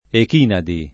ek&nadi] top. f. pl. (Gr.) — es. con acc. scr.: dinanzi alle dentate Echìnadi [din#nZi alle dent#te ek&nadi] (D’Annunzio) — gr. mod. ᾿Εχινάδες (dem. Εχινάδες) / Echinádes / Ehinádes [eh’in#DeS]